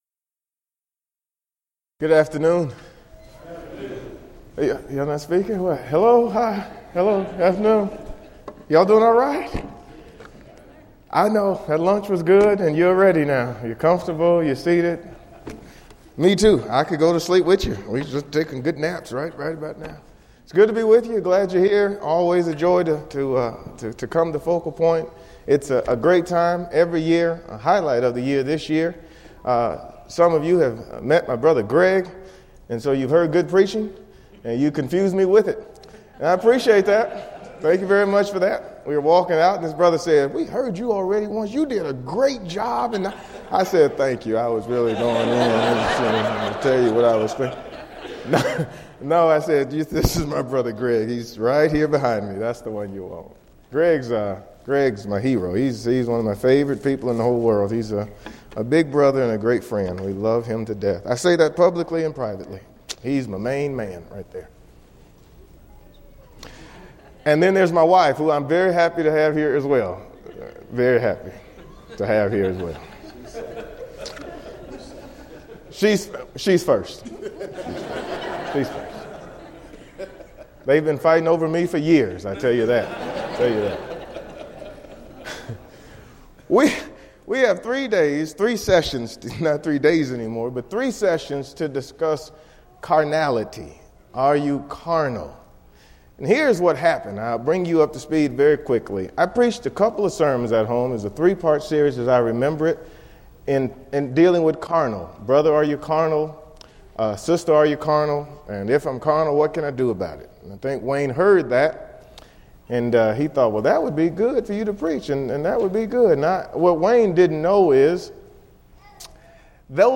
Title: Midday Keynote: Christian, Are You Carnal?
Preacher's Workshop
lecture